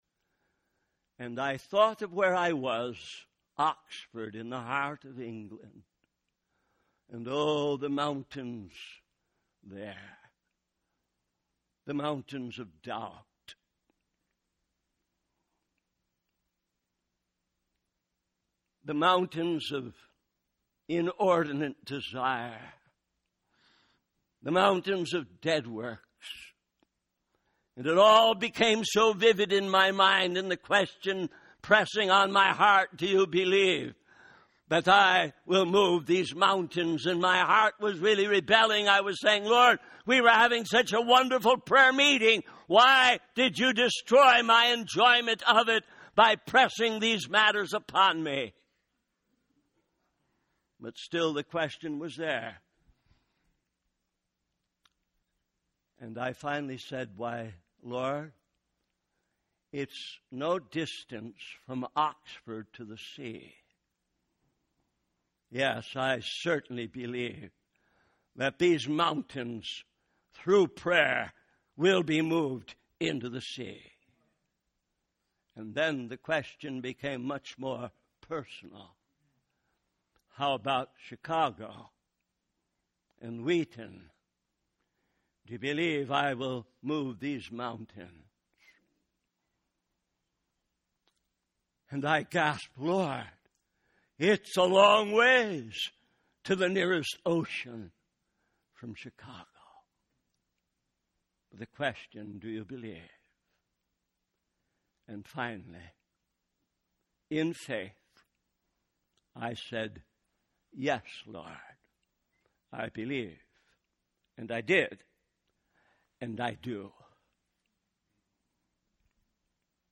In this sermon, the preacher focuses on the message of John the Baptist and the different groups of people who responded to his preaching. He emphasizes the importance of bringing forth fruits in keeping with repentance.